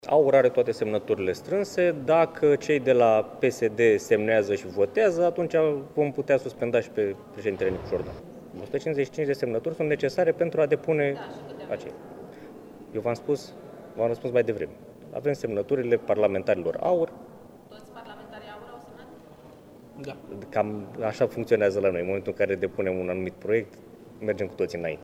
Deputatul AUR, Alin Avrămescu: „Dacă semnează cei de la PSD și votează, atunci îl vom putea suspenda și pe președintele Nicușor Dan”